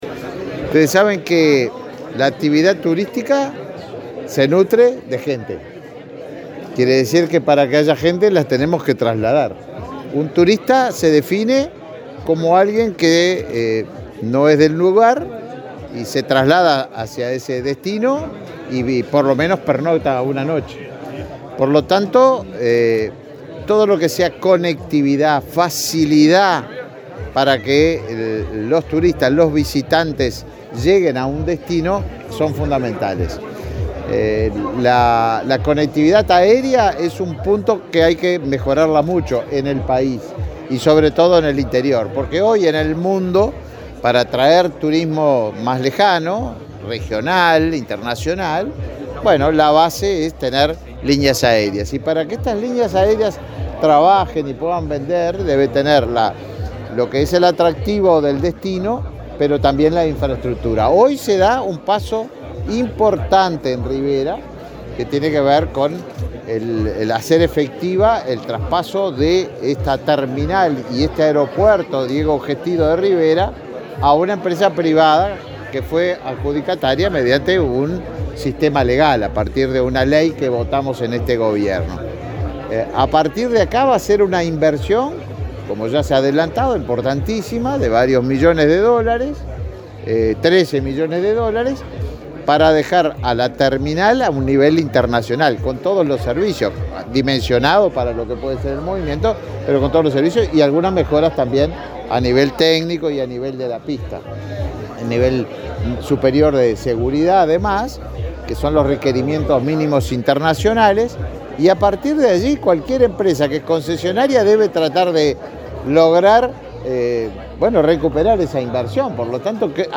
Declaraciones a la prensa del ministro de Turismo, Tabaré Viera
El ministro de Turismo, Tabaré Viera, participó este viernes 22 en el acto en el que la empresa Corporación América Airports se hizo cargo de la